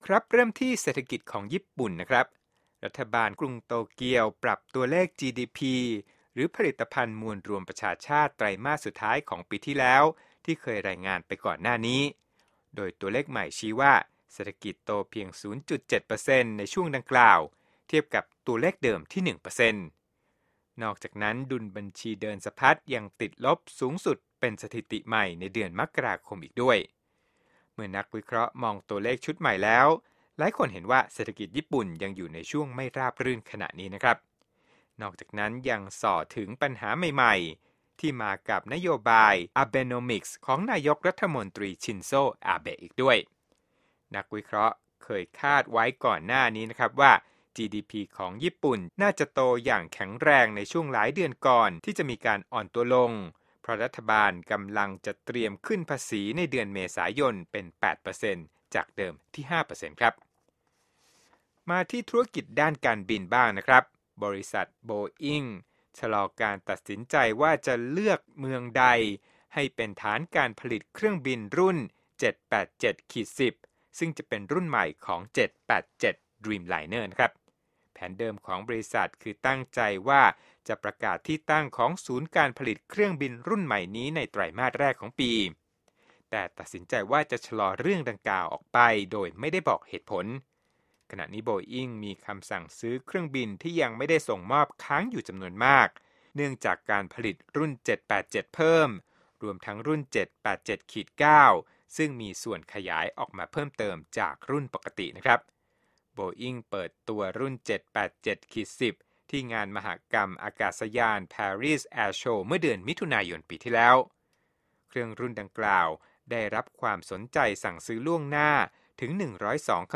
Edward Snowden โฟนอินการประชุมที่รัฐเท็กซัสและเรียกร้องให้คนช่วยกันผลักดันให้เกิดการเปลี่ยนแปลง